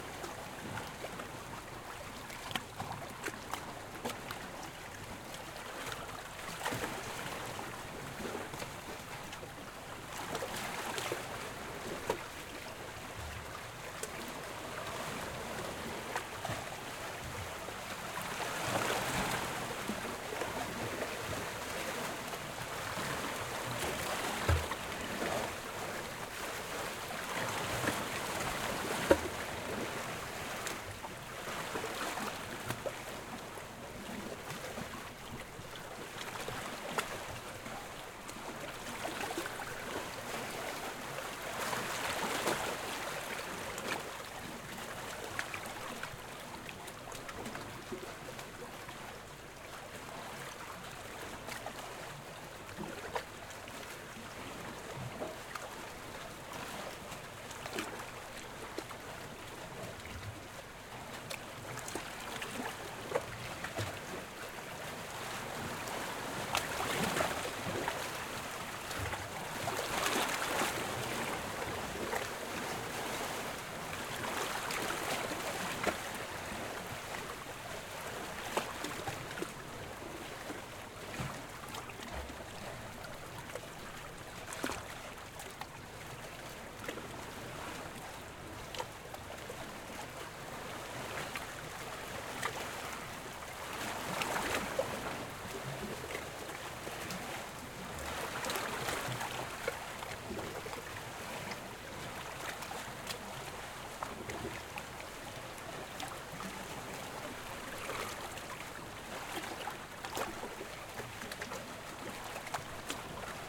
Port-ambience,-small-waves-hitting-boats.oga